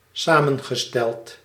Ääntäminen
Synonyymit corps composé mot composé Ääntäminen France: IPA: [kɔ̃.po.ze] Tuntematon aksentti: IPA: /kɔ̃pɔze/ Haettu sana löytyi näillä lähdekielillä: ranska Käännös Ääninäyte 1. samengesteld Suku: m .